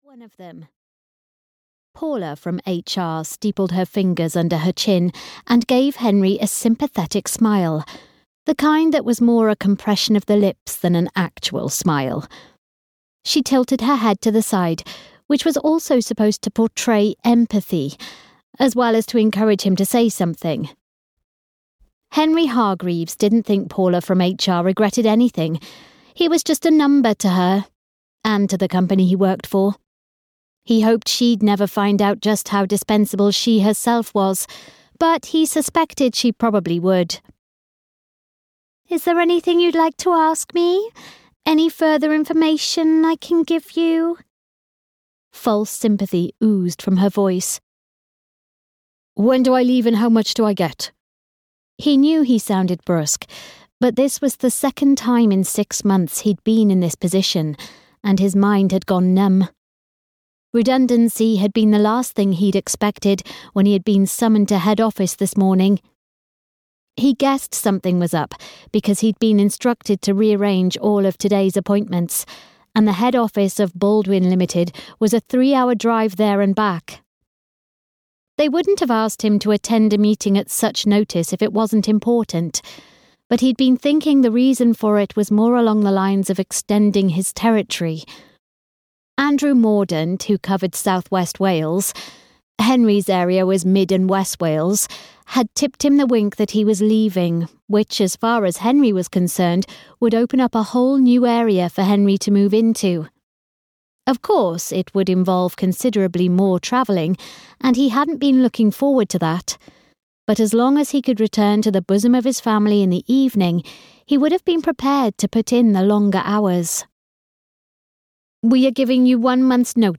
Make Do and Mend at Applewell (EN) audiokniha
Ukázka z knihy